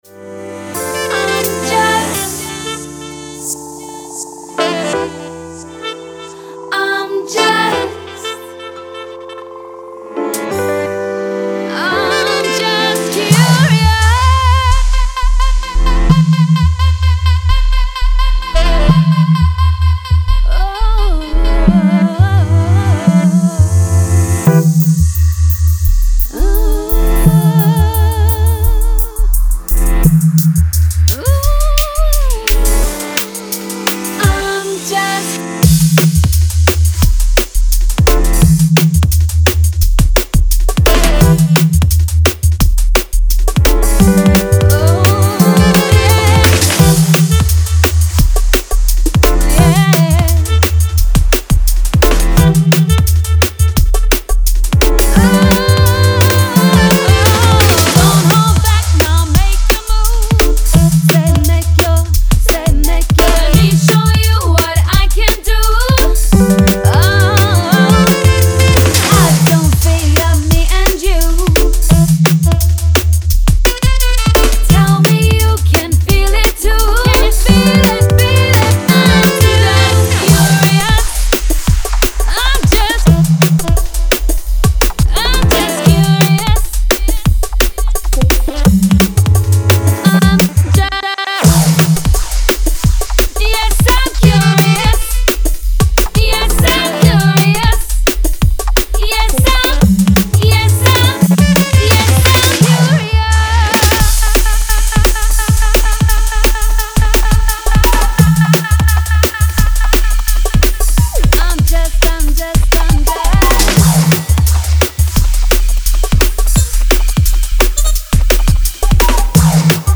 huge vocal anthem